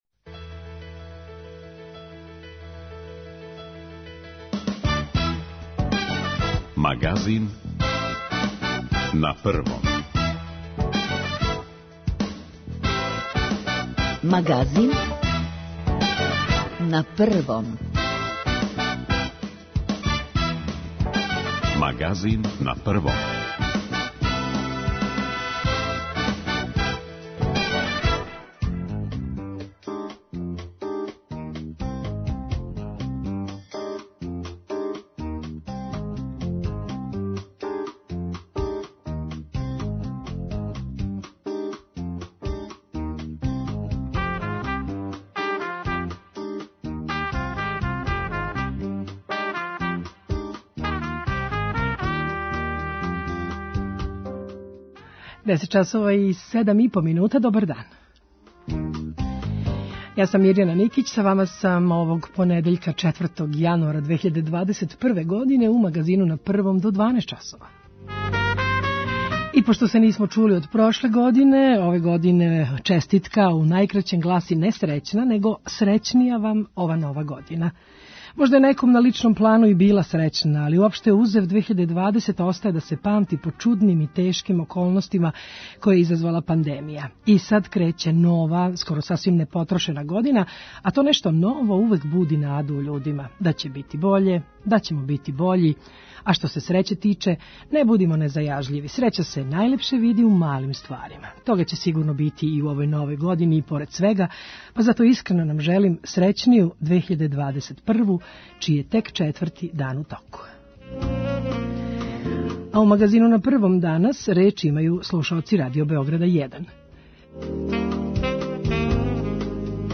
Као и сваког првог понедељка у месецу, а овај је и први у Новој години, део емисије резервисан је за разговоре са слушаоцима о нашем програму, за коментаре, критике, похвале.
О празничној атмосфери и актуелној епидемиолошкој ситуацији у нашој земљи обавестиће нас дописници Радио Београда, а чућемо како су протекли новогодишњи празници и какво је тренутно стање, у вези са пандемијом коронавируса, у неколико европских земаља.